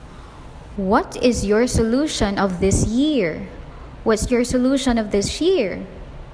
當單字的語尾爲「s」，而下一個單字的語首是「y」時，則會發成「ʃ」 的音。
this⌒year                disyar